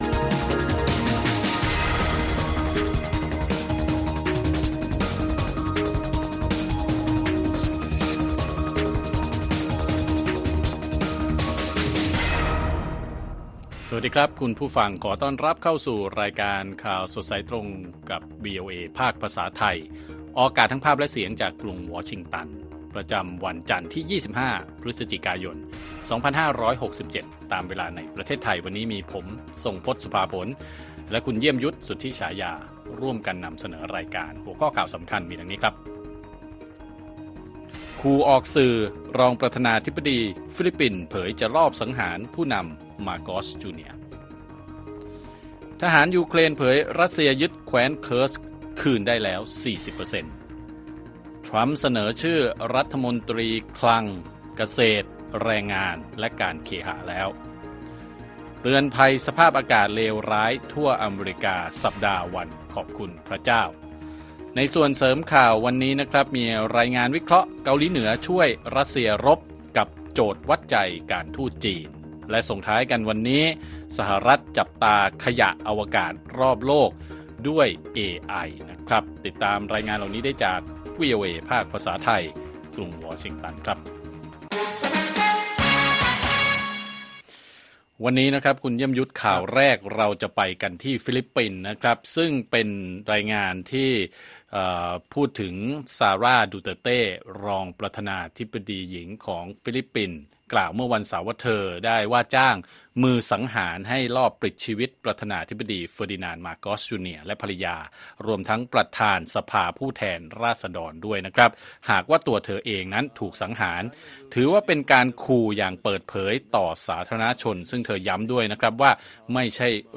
ข่าวสดสายตรงจากวีโอเอไทย จันทร์ ที่ 25 พ.ย. 67